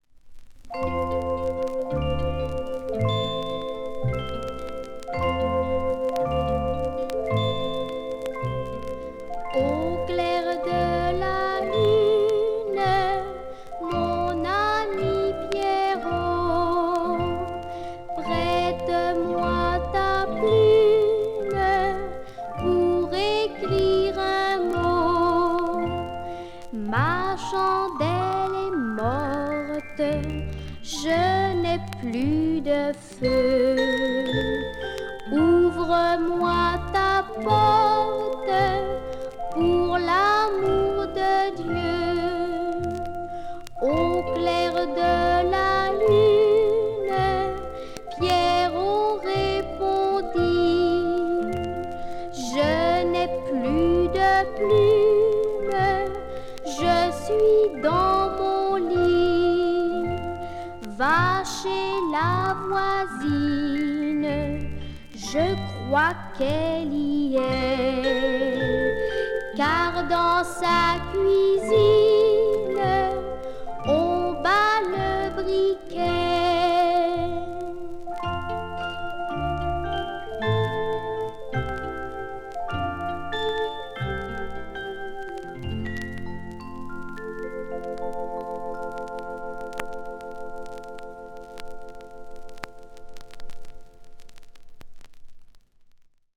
Gute-Nacht- und Schlaflied
die beruhigende und eingängige Melodie